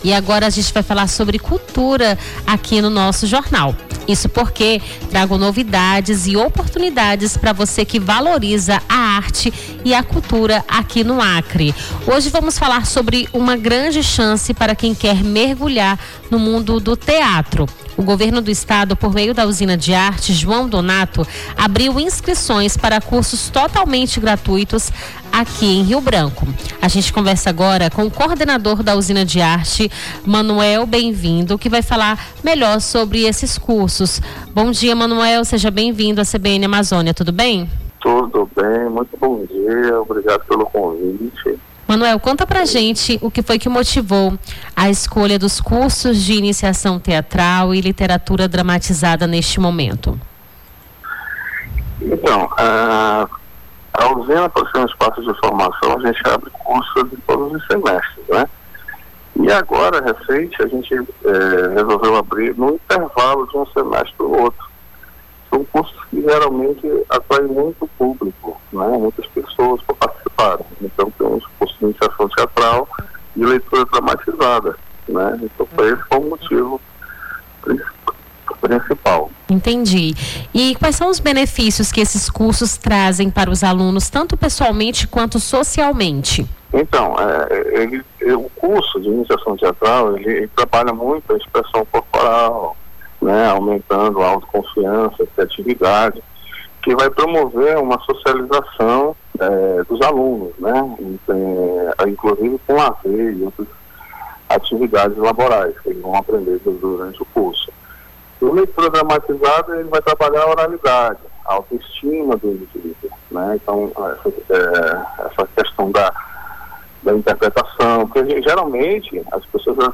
Nome do Artista - CENSURA - ENTREVISTA CURSOS LIVRES DA USINA DE ARTE (02-06-25).mp3